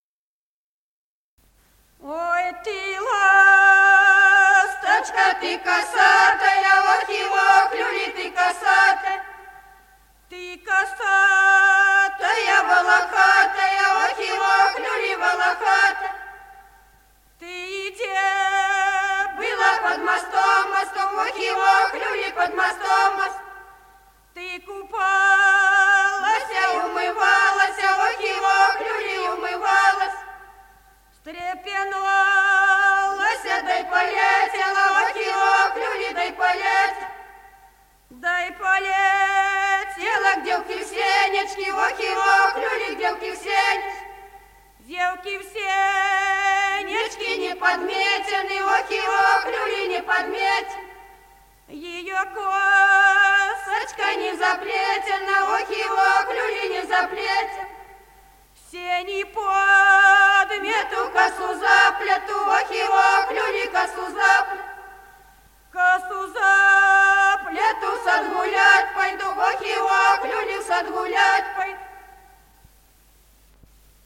Народные песни Стародубского района «Ой, ты ласточка», юрьевская таночная.
Записано в Москве, декабрь 1966 г., с. Курковичи.